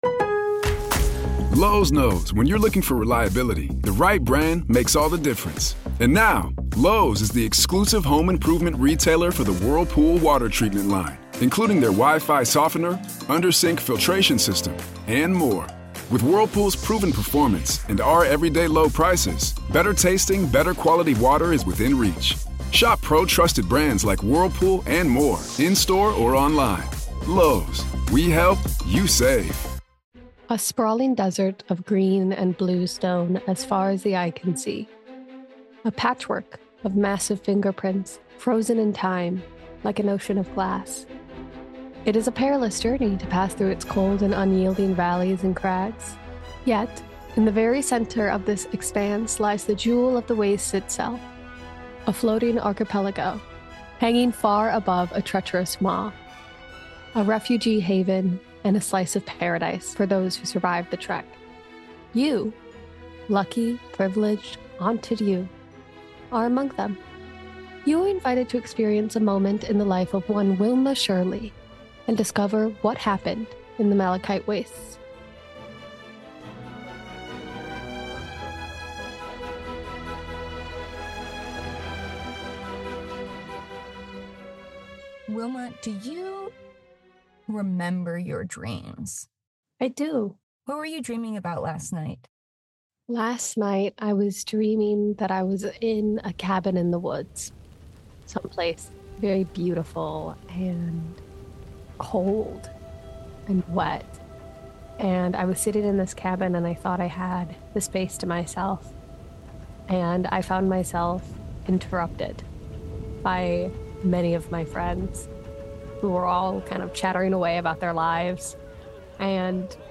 … continue reading 13 episodes # Lifestyle # Games and Gambling # Audio Drama # Malachite Wastes Productions